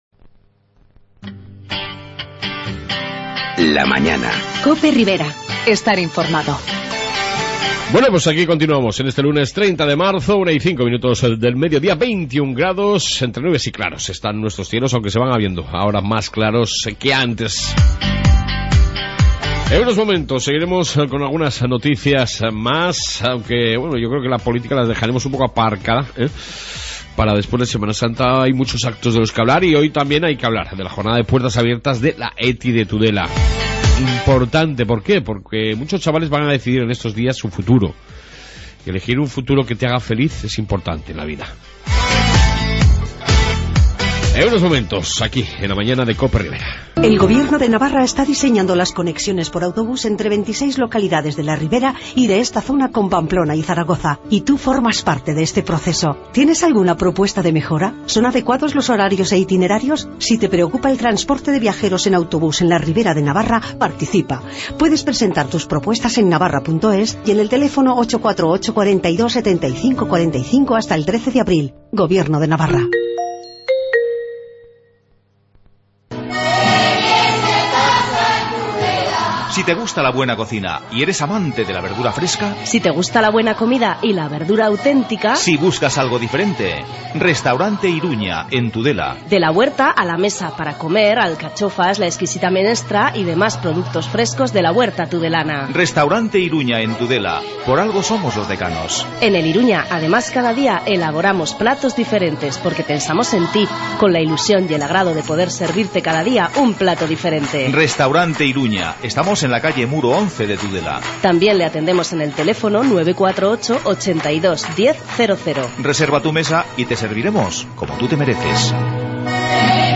Noticias y entrevista